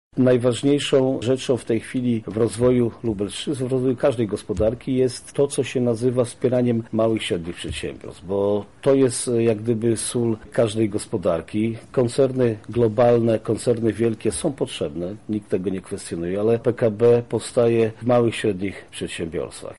O potrzebie wspierania tych firm mówi Marszałek Województwa Lubelskiego Jarosław Stawiarski: